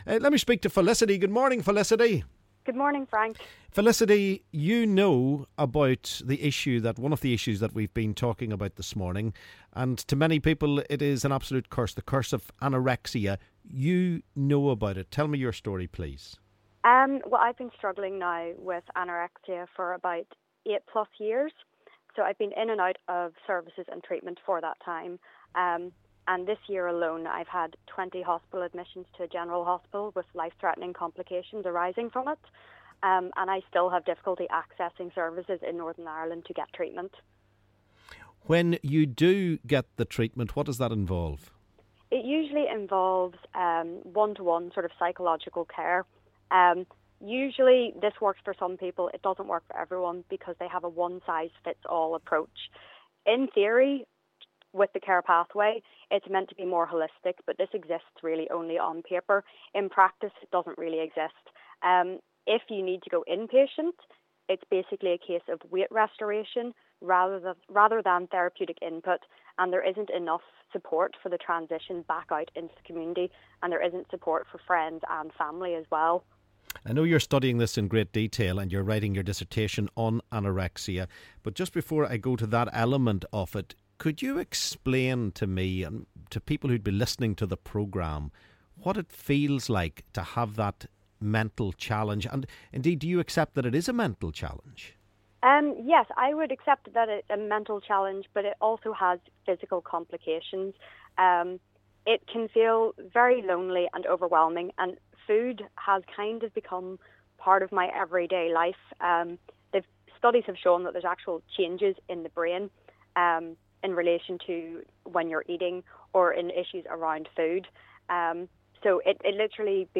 LISTEN: Callers tell us their stories about deaIing with anorexia & eating disorders